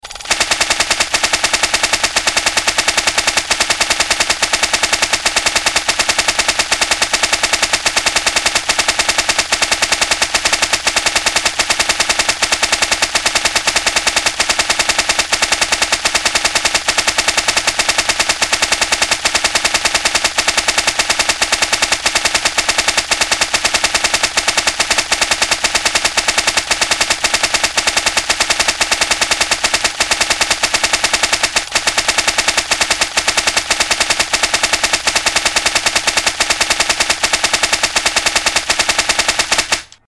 Genre: Nada dering lucu